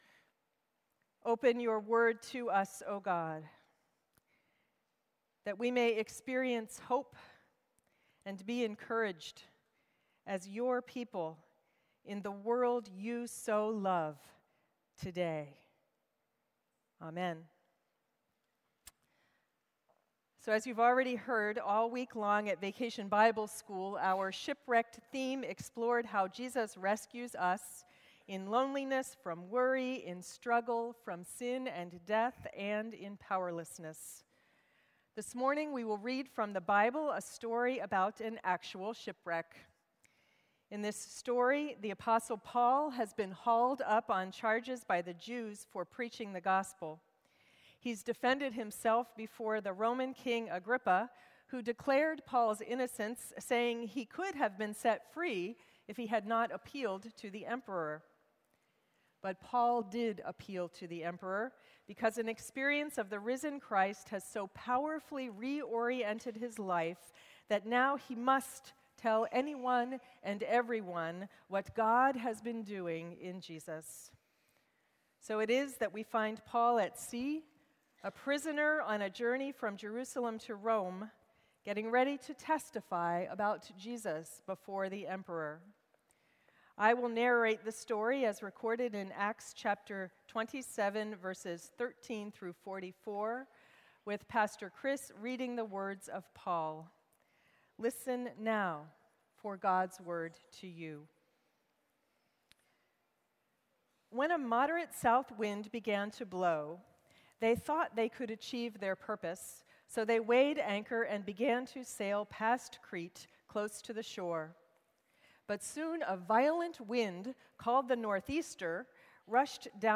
Sermons - Crossroads